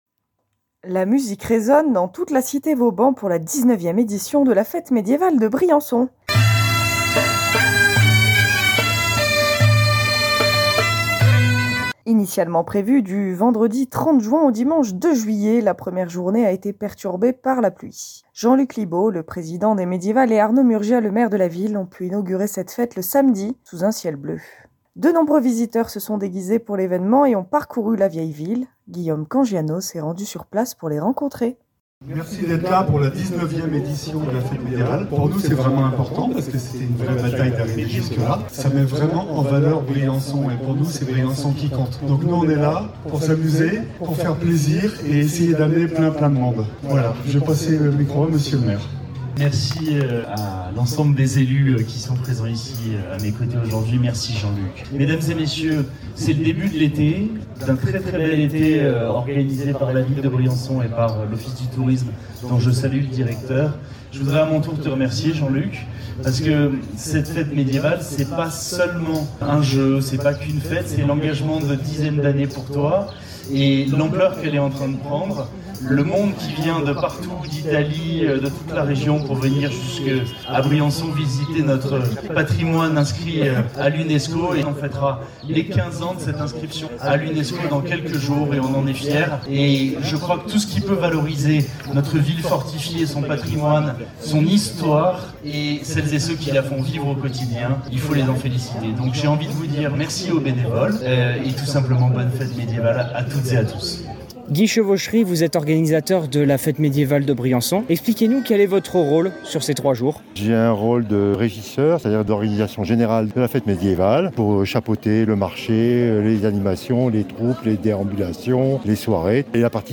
Du monde en costume pour la fête médiévale (2.85 Mo) La musique résonne dans toute la cité Vauban pour la 19ème édition de la fête médiévale de Briançon.